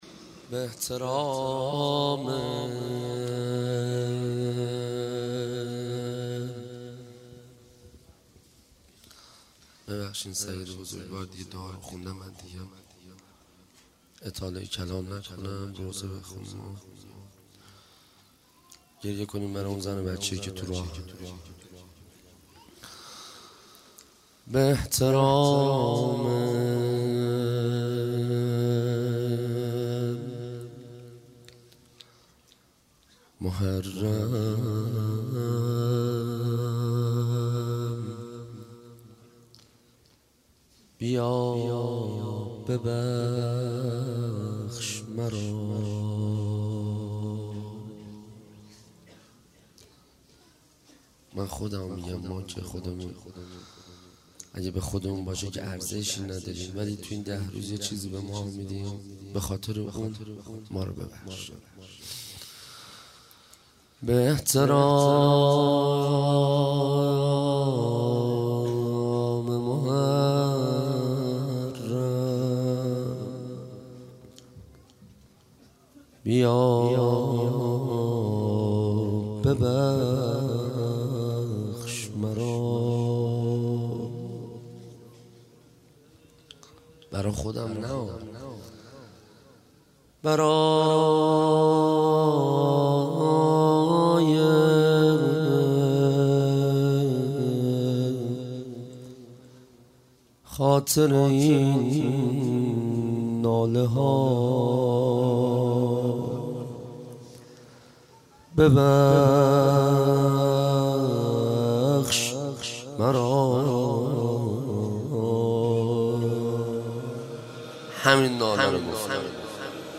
روضه حضرت رقیه